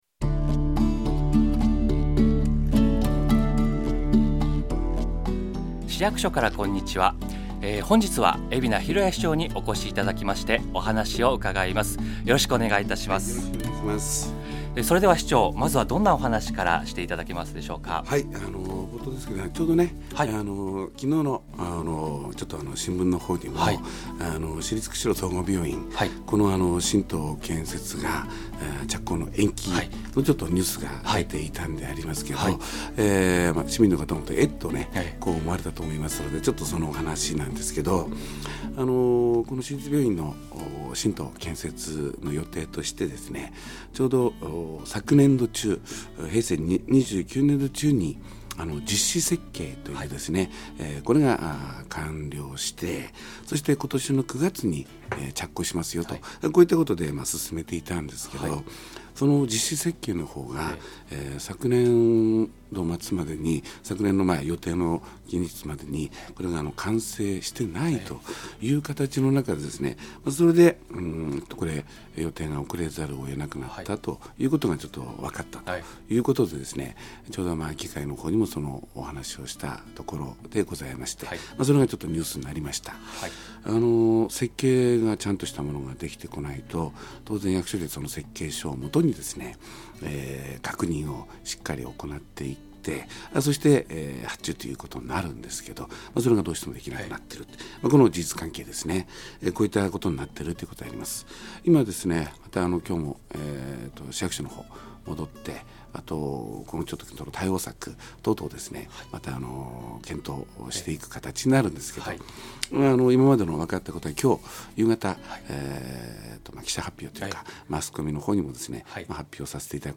市長が出演した番組を掲載しています